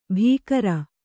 ♪ bhīkara